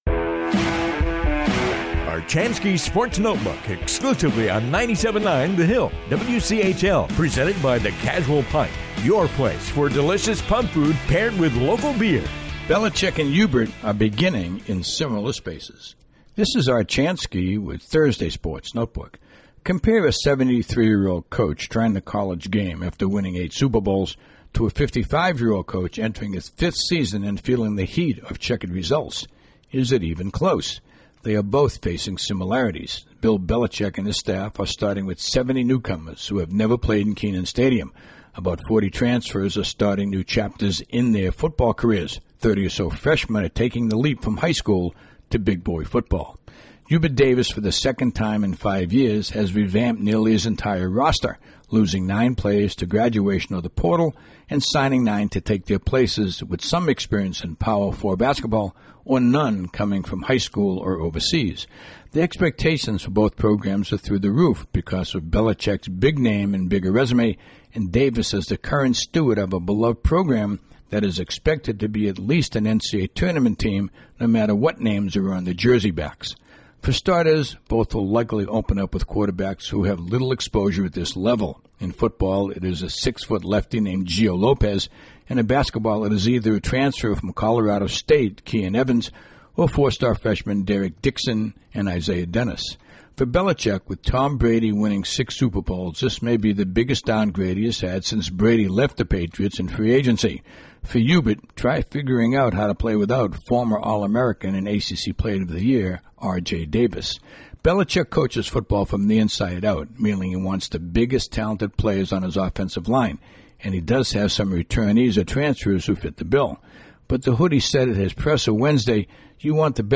commentary airs daily on the 97.9 The Hill WCHL